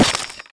Playerfrozen Sound Effect
playerfrozen.mp3